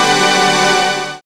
1905L SYNPAD.wav